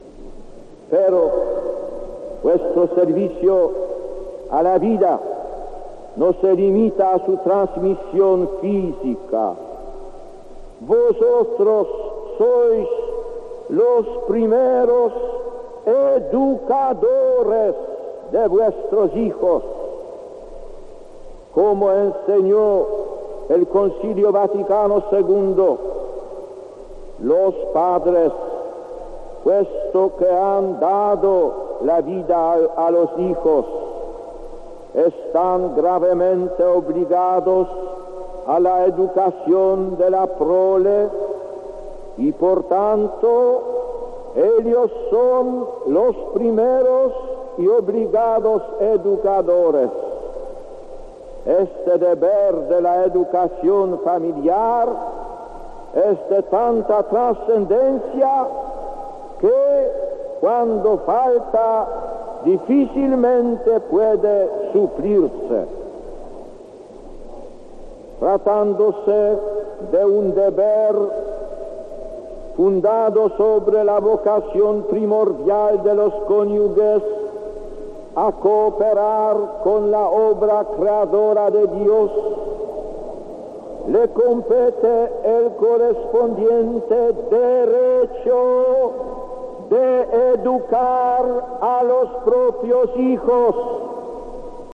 Gracias a los documentos sonoros de la Cadena COPE hemos podido volver a escuchar la homilía de ese día y resulta sorprendente lo actual que pueden ser las palabras de Karol Wojtyla en una Plaza de Lima abarrotada de gente.